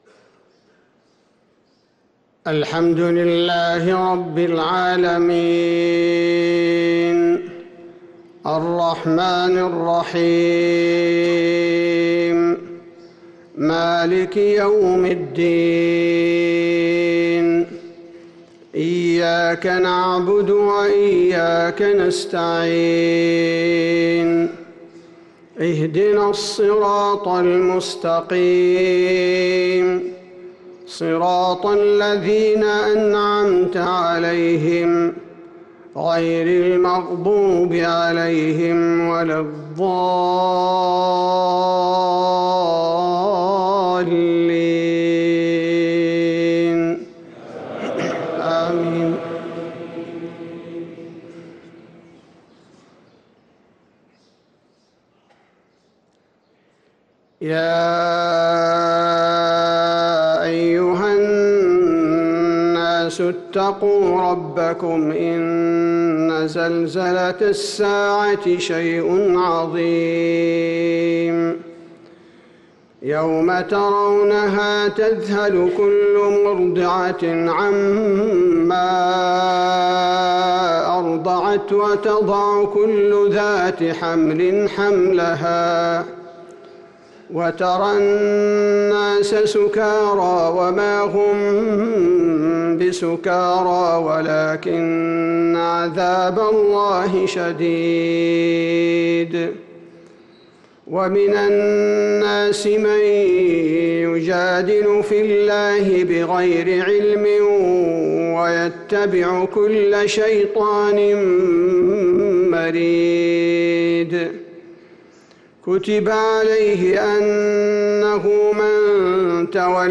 صلاة الفجر للقارئ عبدالباري الثبيتي 16 رجب 1445 هـ